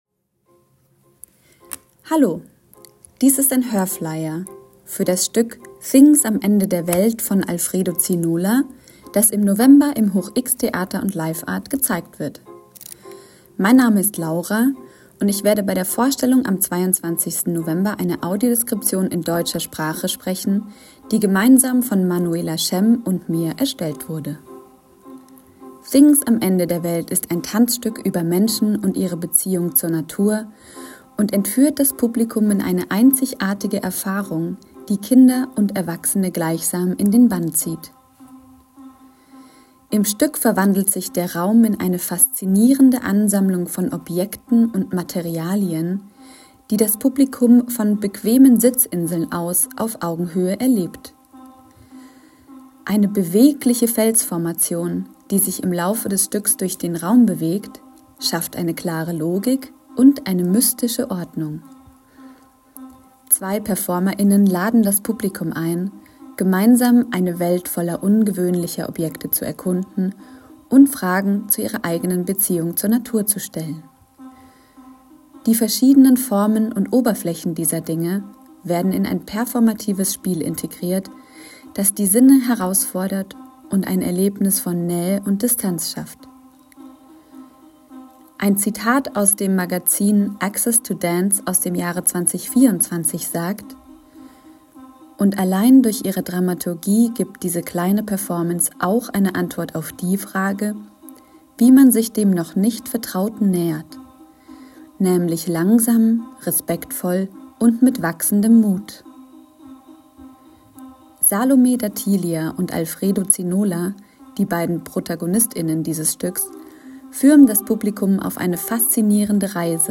Hier ist der Audioflyer zu Things am Ende der Welt:
Audioflyer-Things-am-Ende-der-Welt.m4a